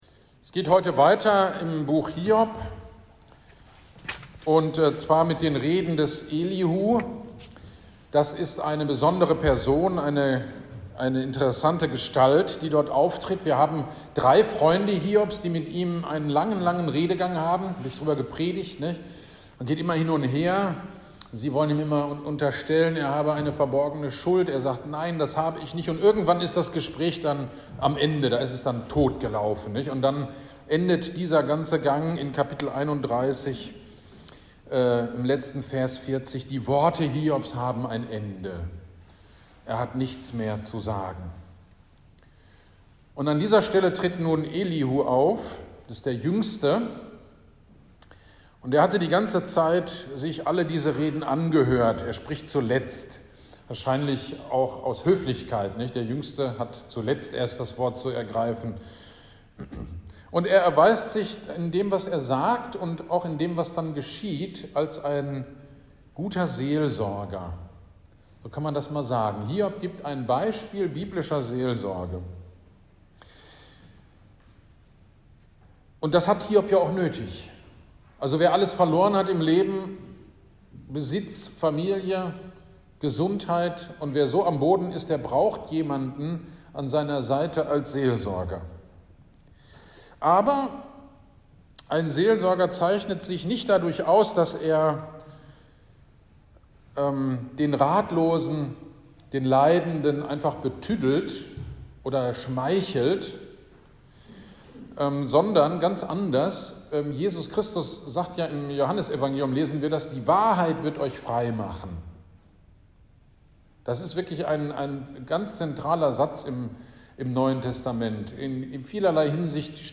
GD am 02.04.23 Predigt zu Hiob 32-37 - Kirchgemeinde Pölzig